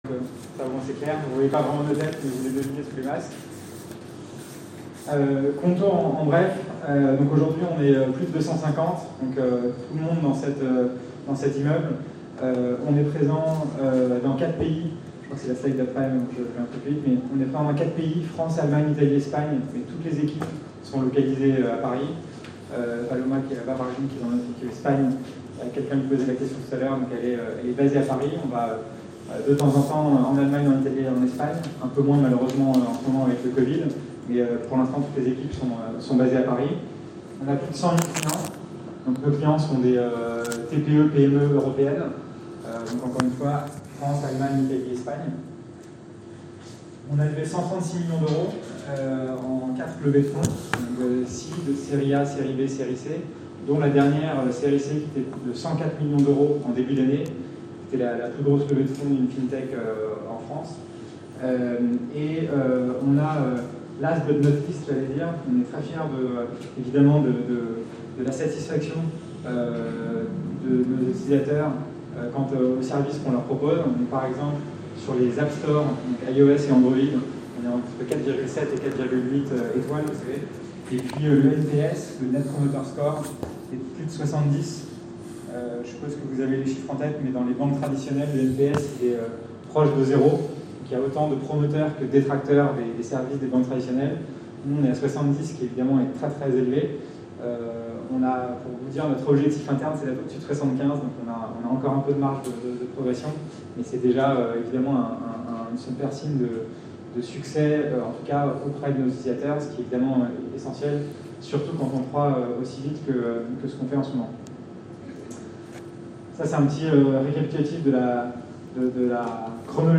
A l’occasion d’un petit-déjeuner presse organisé le mardi 22 septembre dans les locaux de Qonto